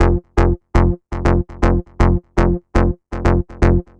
TSNRG2 Bassline 030.wav